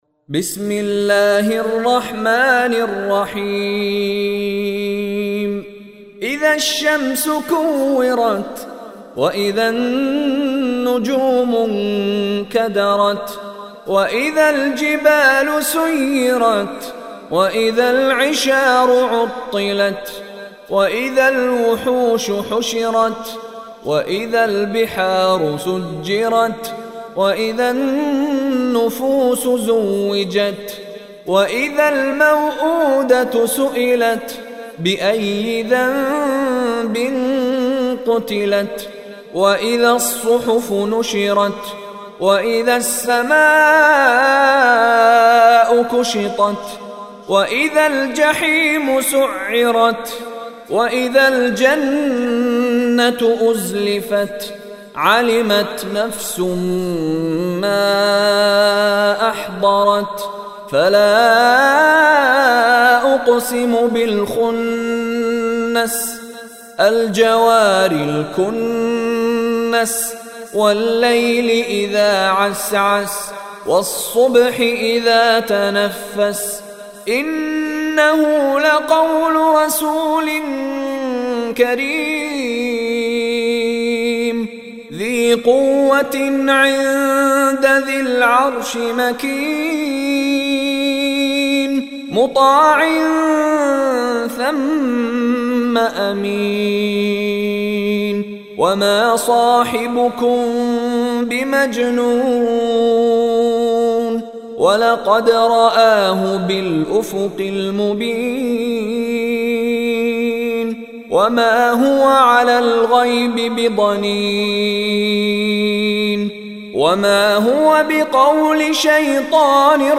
Surah Takwir Recitation by Mishary Rashid
Surah Takwir is 85th Surah or chapter of Holy Quran. Listen online and download beautiful Quran tilawat / recitation of Surah At Takwir in the beautiful voice of Sheikh Mishary Rashid Alafasy.